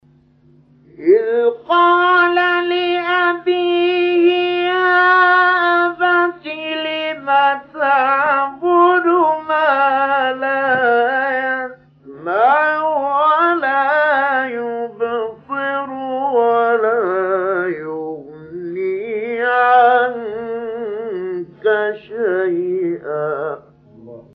تلاوت 42 مریم مقام نکریز شیخ علی محمود | نغمات قرآن
سوره:‌ مریم آیه: 42 استاد:‌ شیخ علی محمود مقام: نکریز إِذْ قَالَ لِأَبِيهِ يَا أَبَتِ لِمَ تَعْبُدُ مَا لَا يَسْمَعُ وَلَا يُبْصِرُ وَلَا يُغْنِي عَنكَ شَيْئًا ﴿٤٢﴾ قبلی